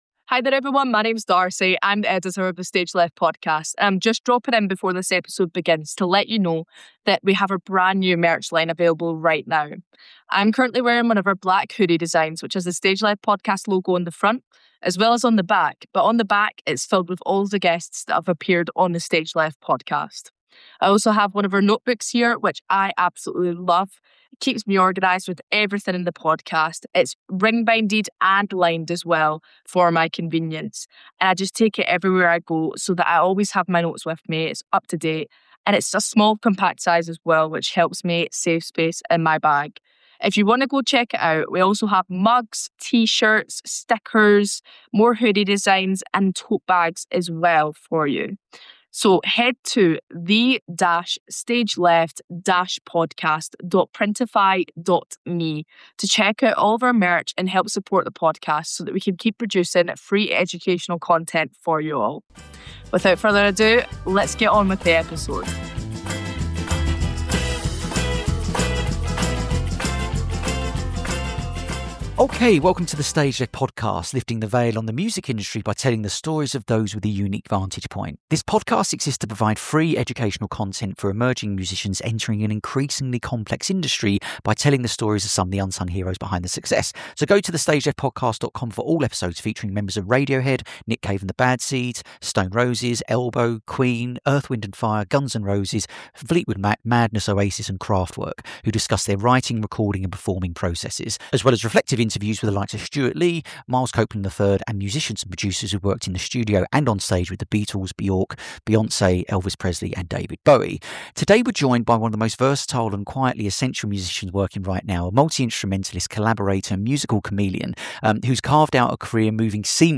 A practical, behind-the-scenes conversation covering creativity, chaos, and staying grounded in the music industry.